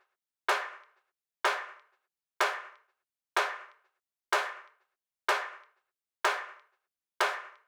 snare groupes.wav